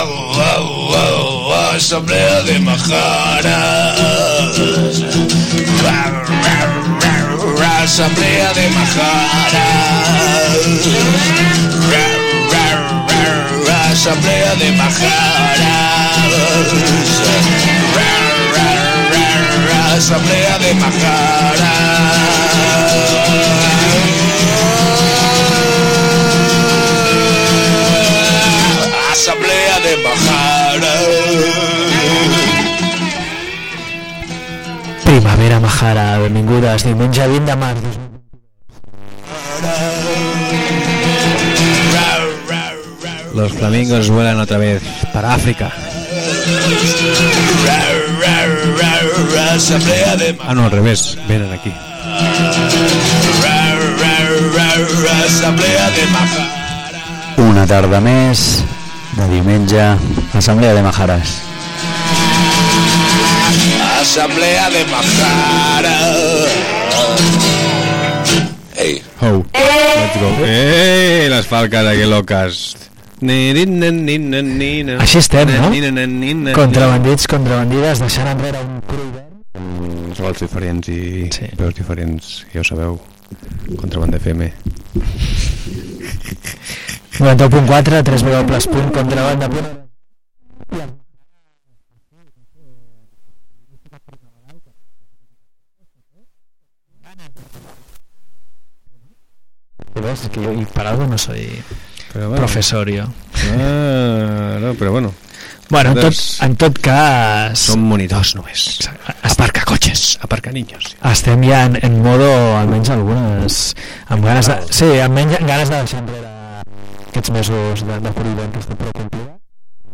Programa que hem trigat a recuperar